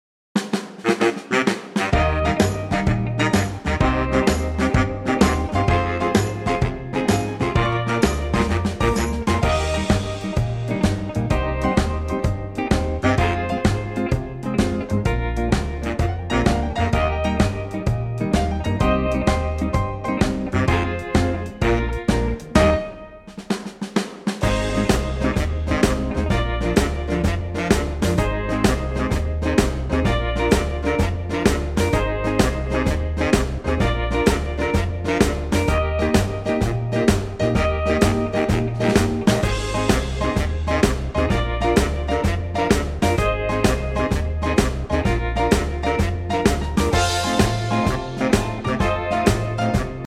Unique Backing Tracks
key - Eb - vocal range - G to F